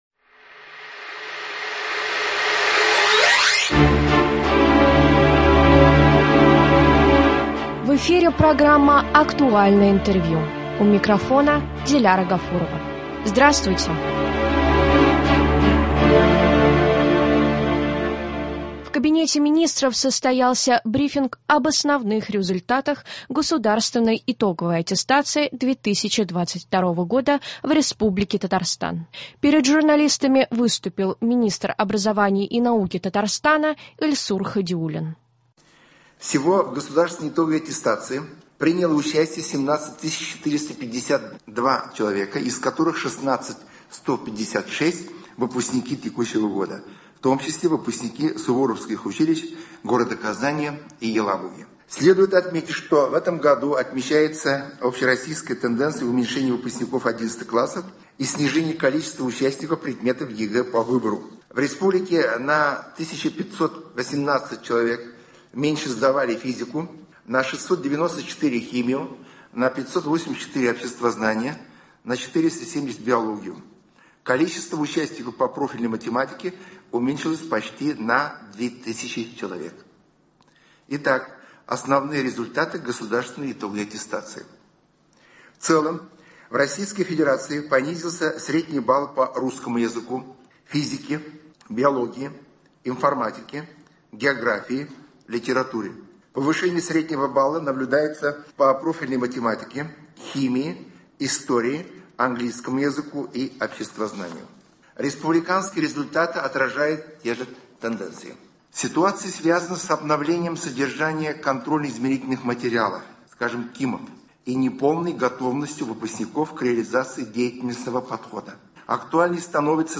Актуальное интервью (20.07.2022)
В Кабинете Министров состоялся брифинг об основных результатах государственной итоговой аттестации 2022 года в Республике Татарстан. Перед журналистами выступил — Министр образования и науки Татарстана Ильсур Хадиуллин.